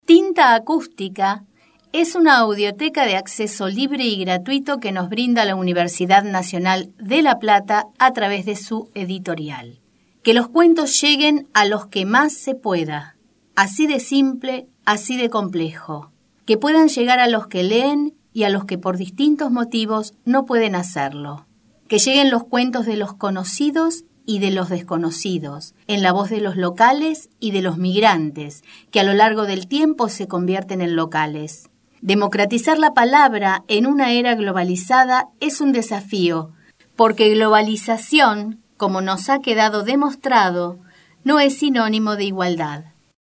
Palabras claves: Narración de cuentos ; Audiolibro